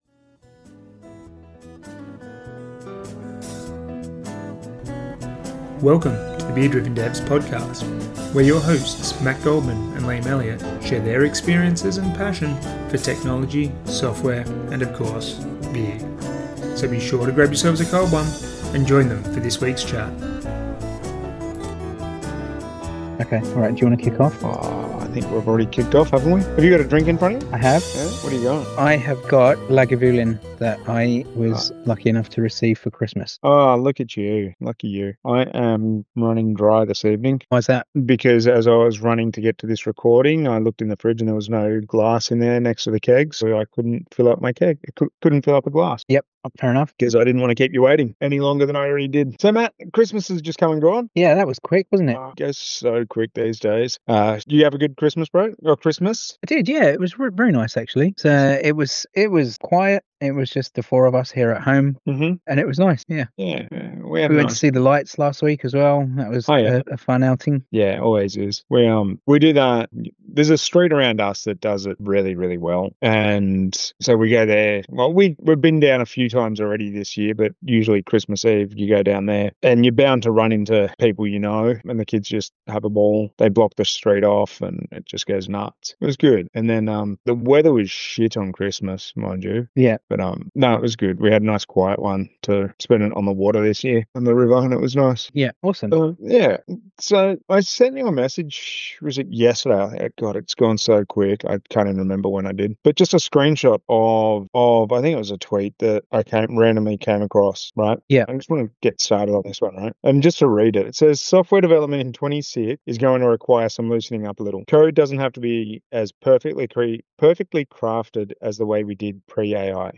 A candid discussion on tech debt, productivity, and what we actually want from our tools.